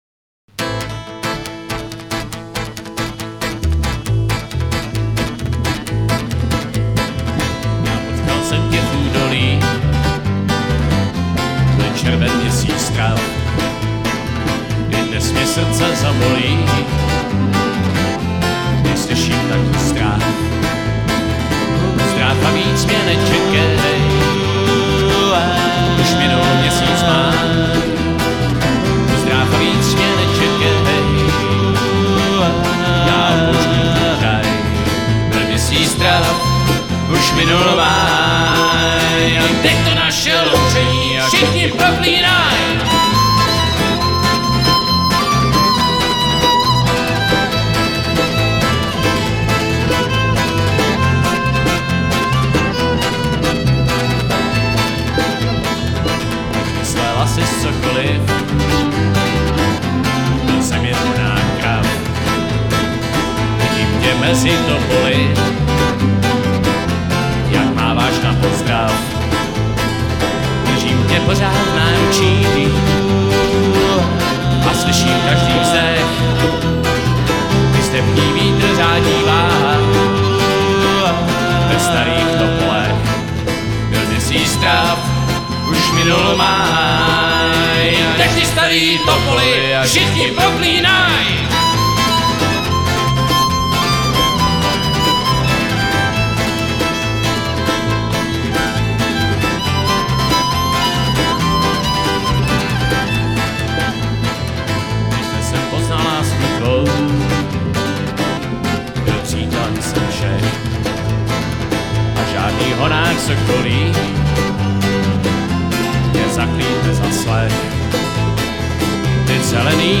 český renesanční country folk rock